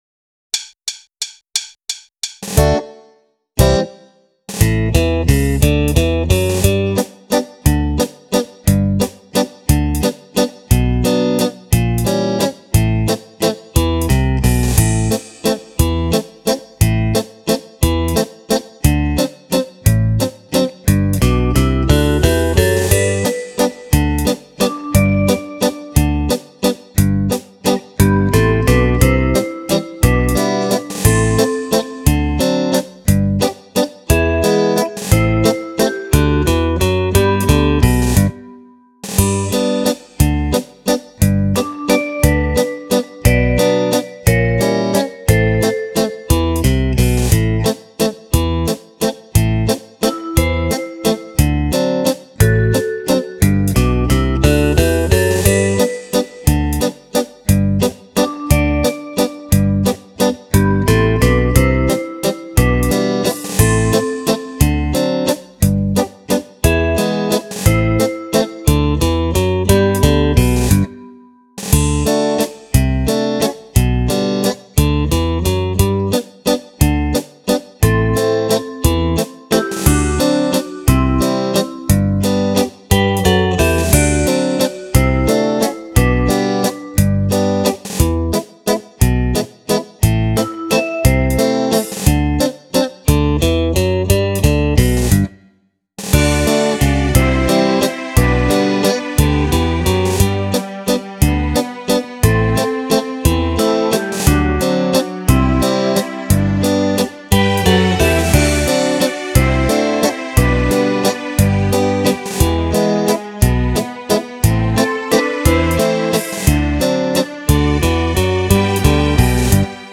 10 ballabili per Fisarmonica
Valzer